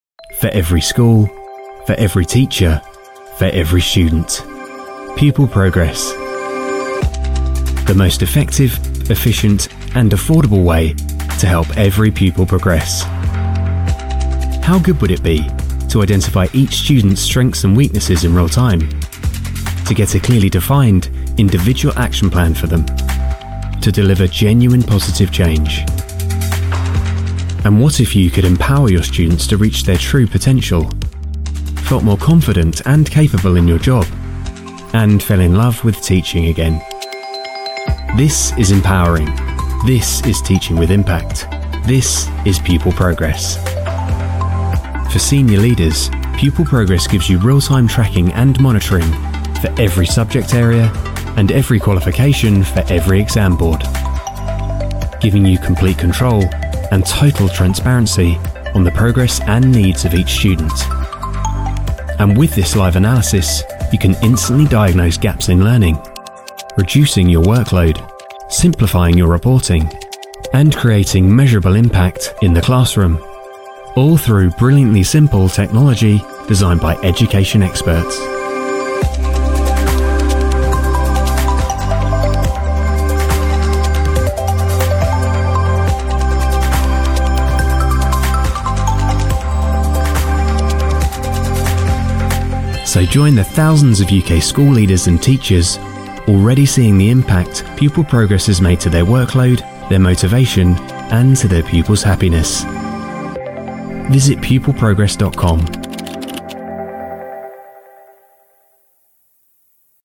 Explainer Video – Pupil Progress
BRITISH MALE VOICE-OVER ARTIST
Warm, neutral (non-regional) English accent
Pupil-Progress-Voiceover.mp3